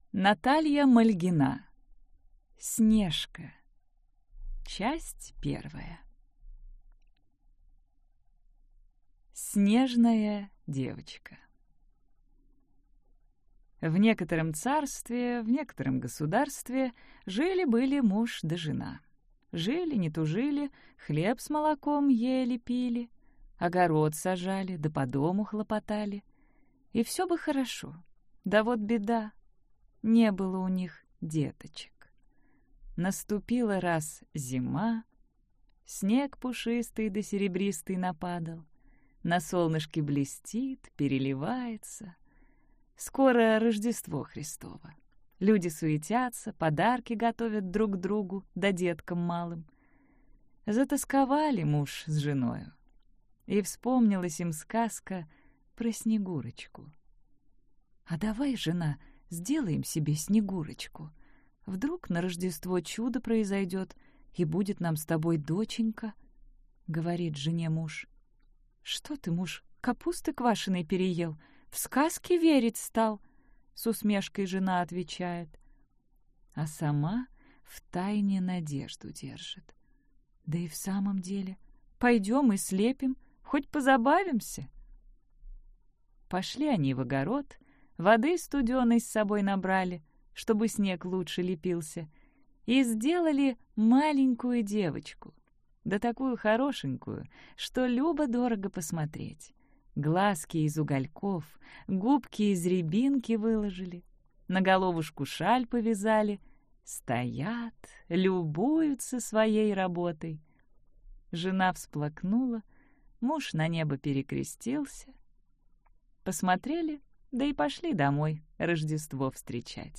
Аудиокнига Снежка | Библиотека аудиокниг